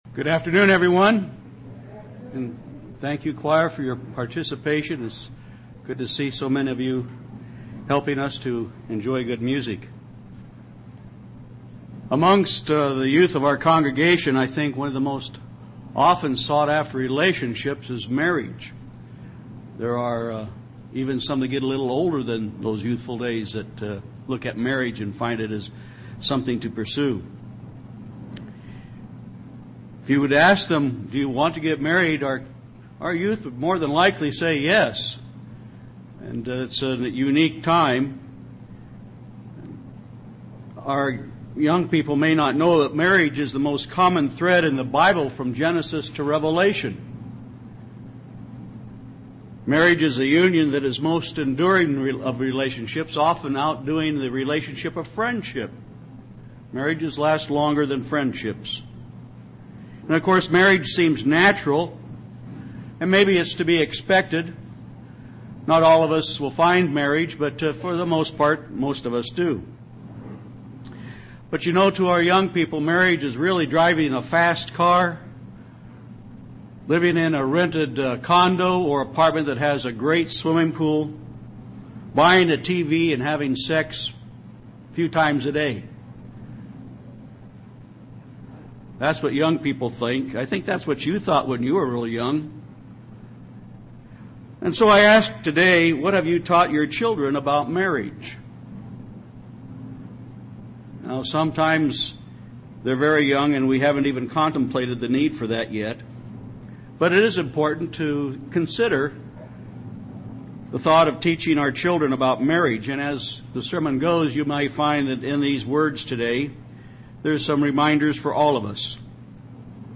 Given in Phoenix Northwest, AZ
UCG Sermon Studying the bible?